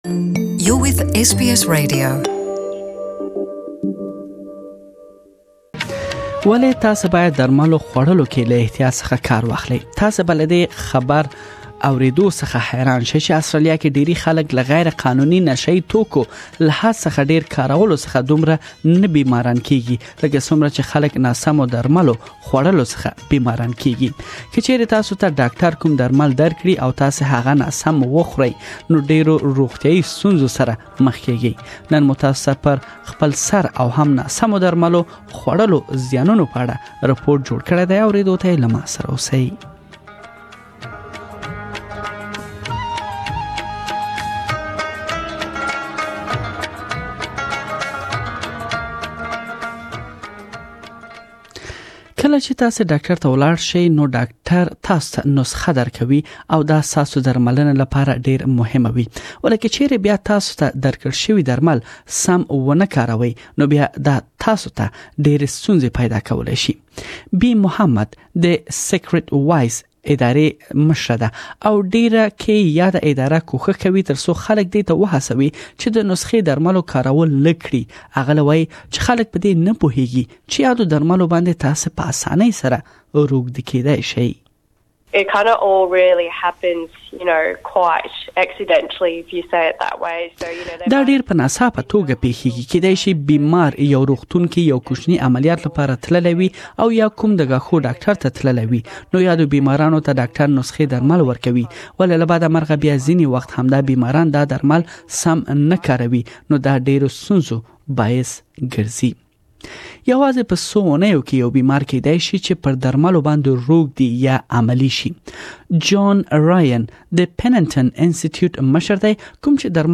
Please listen to the full report in Pashto.